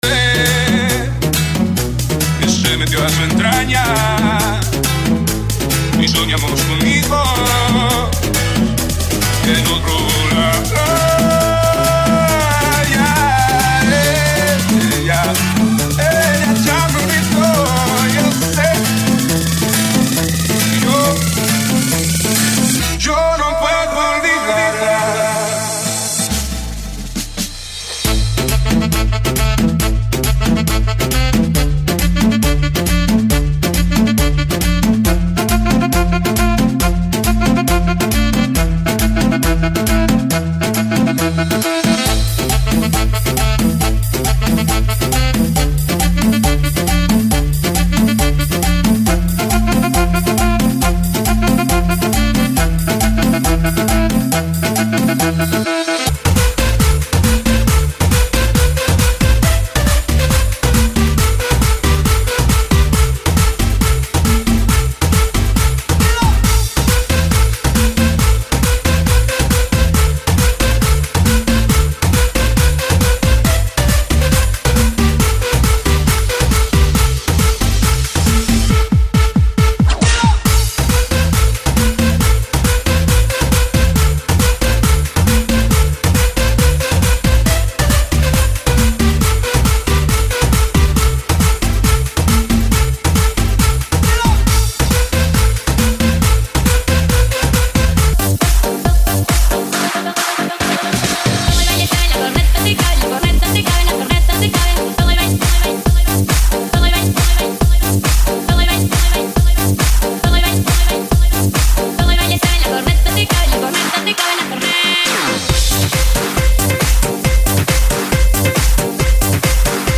GENERO: CIRCUIT REMIX